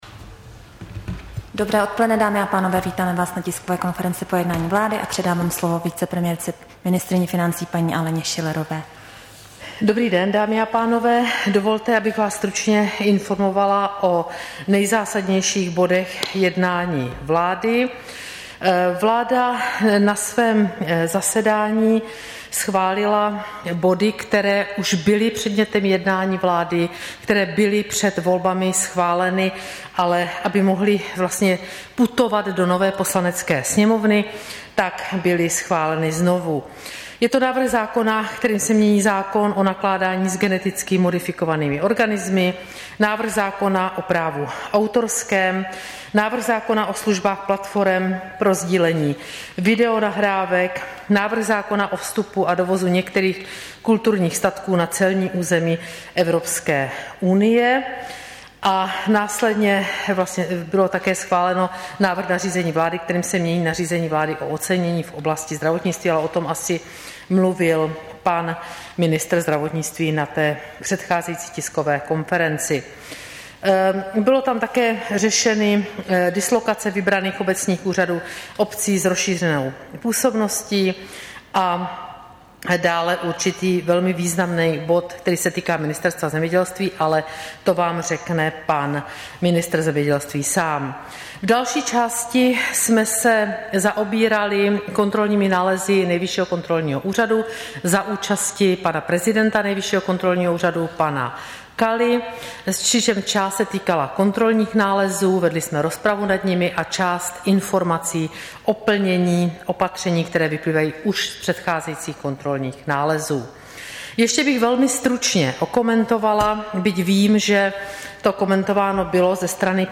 Tisková konference po jednání vlády, 25. října 2021